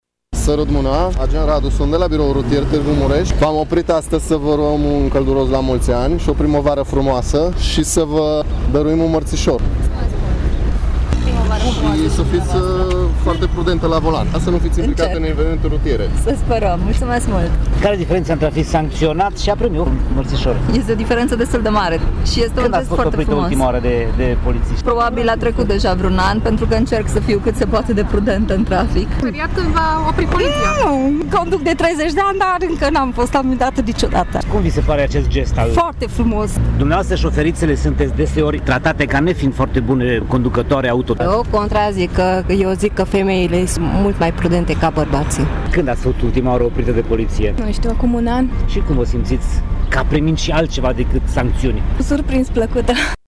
Unele dintre doamnele și domnișoarele aflate la volan au avut emoții cînd au fost oprite de polițiști, însă s-au linștit când au primit în dar mărțișorul: